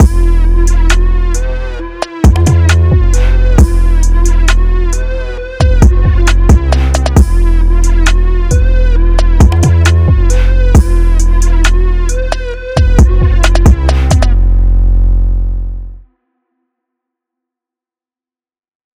drill (!)_Current.wav